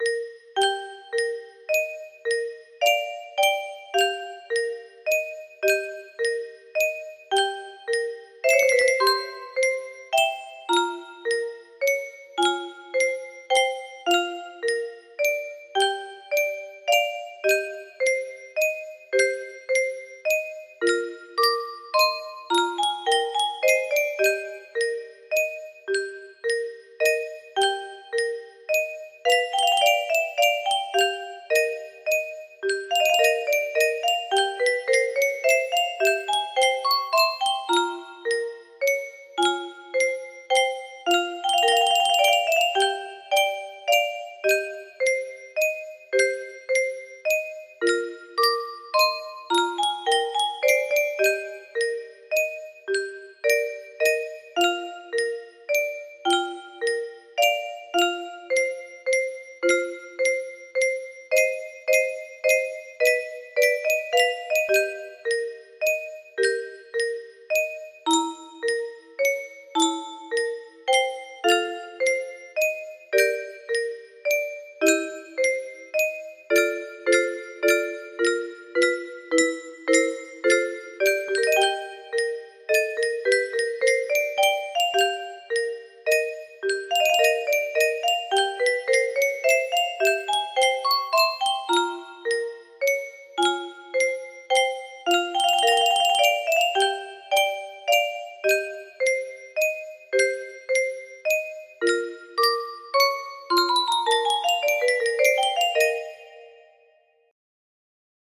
Nocturne music box melody
Grand Illusions 30 (F scale)